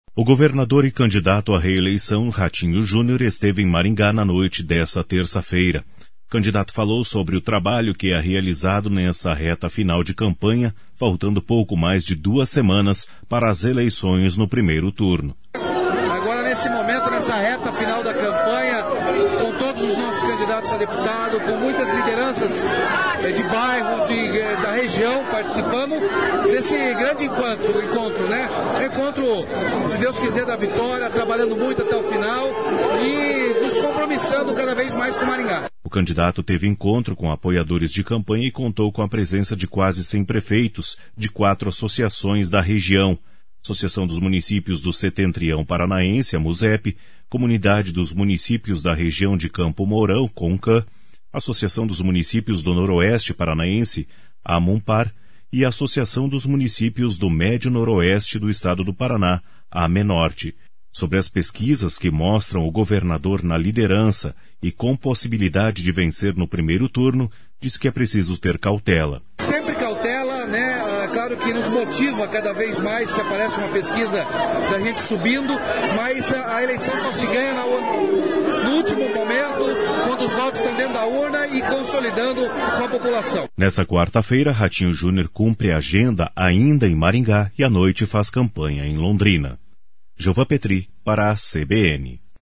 O candidato falou sobre o trabalho que é realizado nessa reta final de campanha faltando pouco mais de duas semanas para as eleições no primeiro turno.